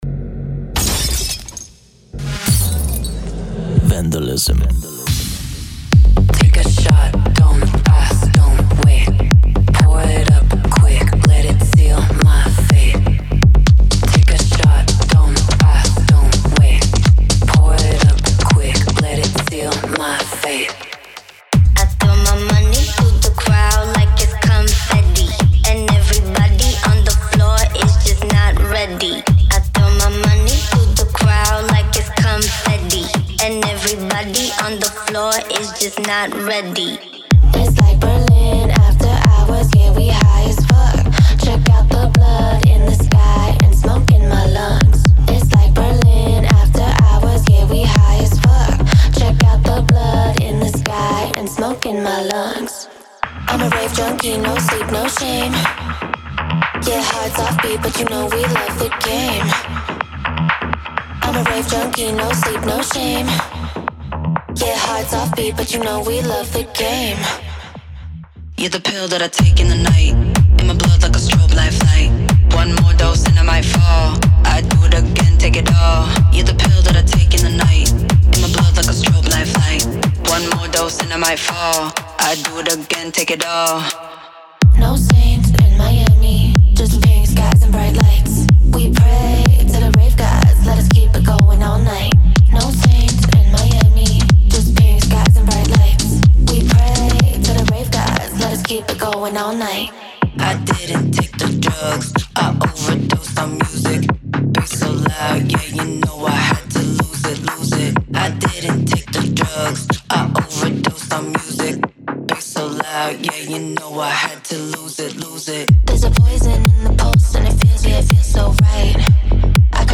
Type: Samples
Electro House Melodic Techno Techno Vocals
• 40 Dry & Wet Vocals
• Tempo: 124 BPM